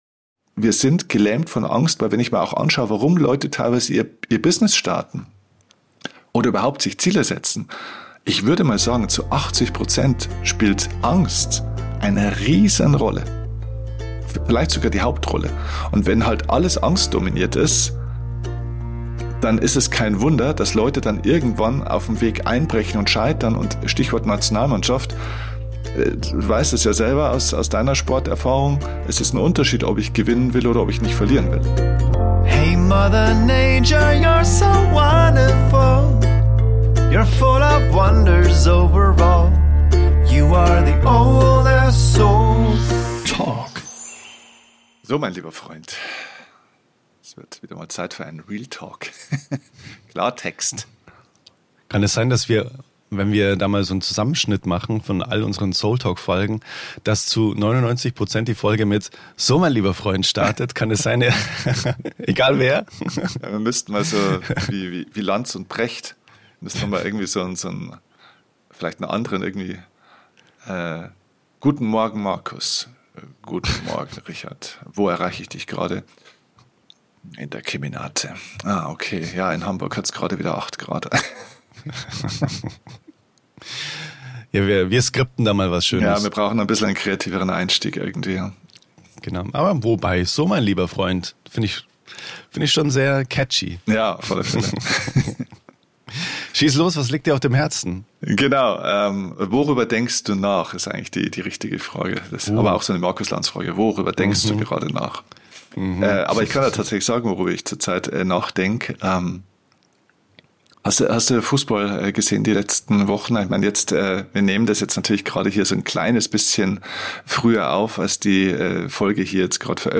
Ein offenes Gespräch über alte Muster, geistige Enge und den Weg zurück in Vertrauen und innere Weite.